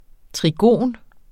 Udtale [ tʁiˈgoˀn ]